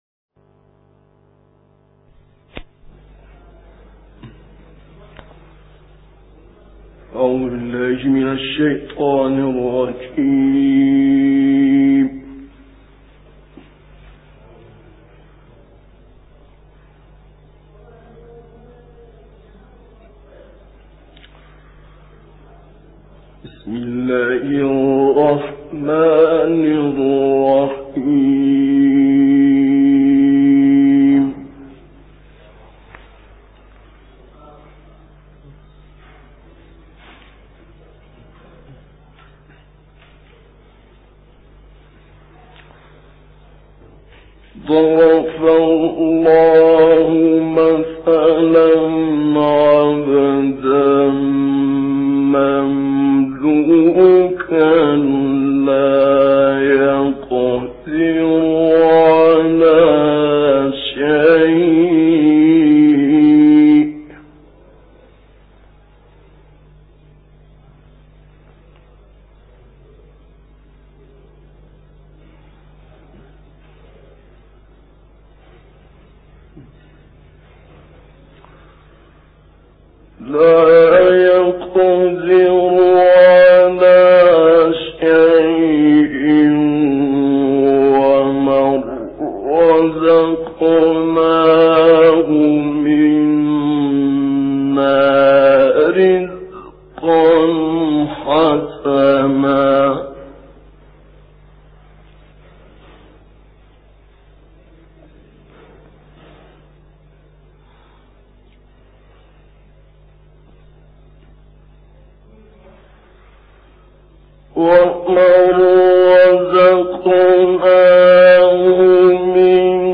May God make us meet your good expectations - El Forqaan For Recitations and Quran Science